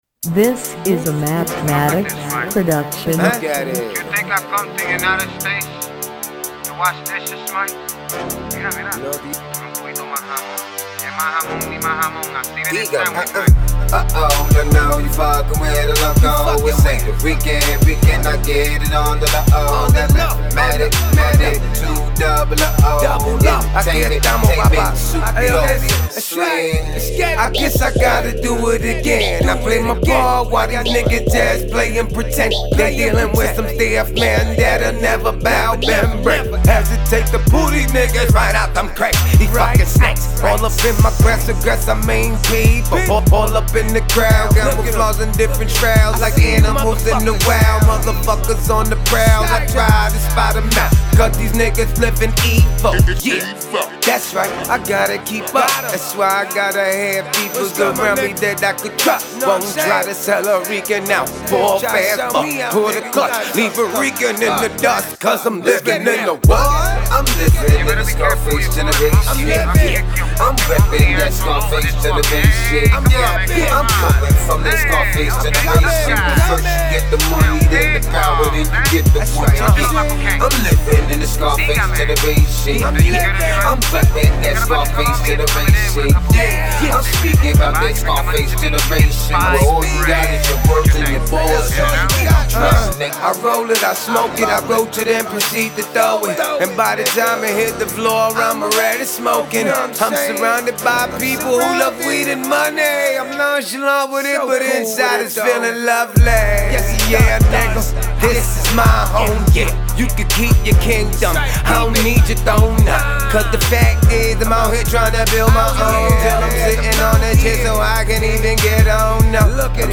Hiphop
Trap track